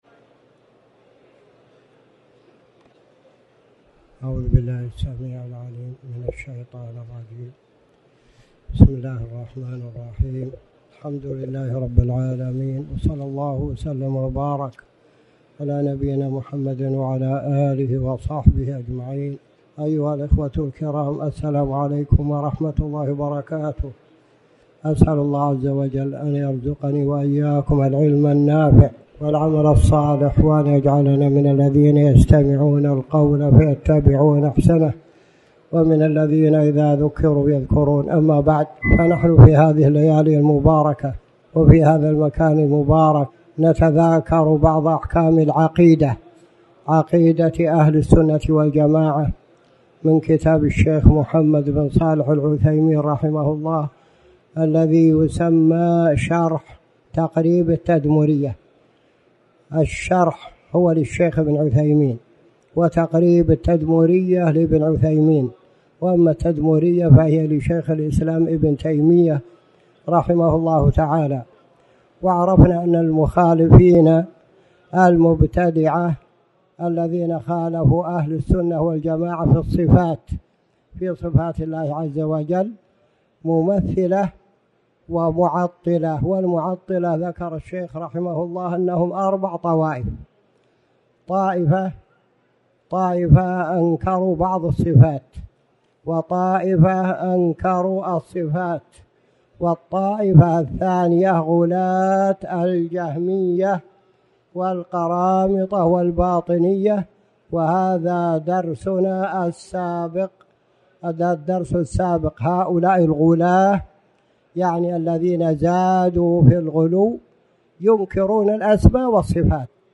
تاريخ النشر ١٥ ذو القعدة ١٤٣٩ هـ المكان: المسجد الحرام الشيخ